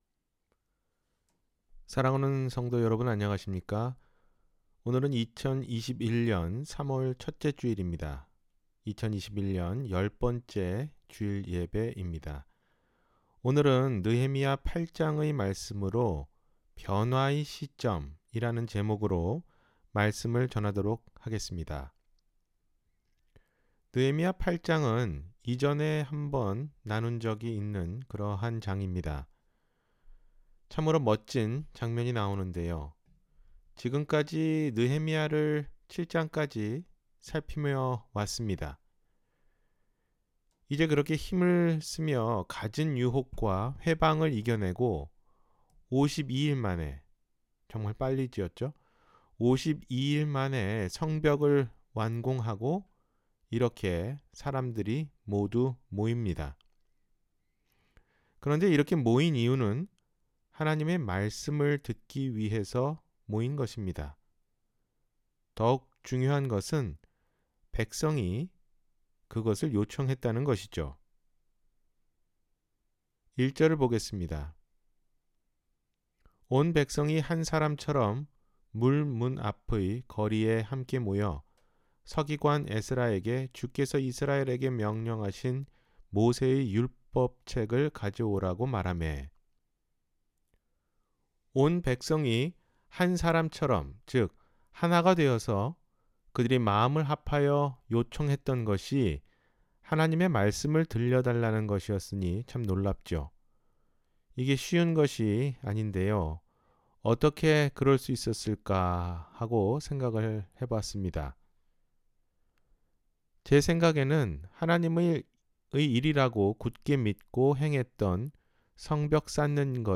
변화의 시점 – 주일설교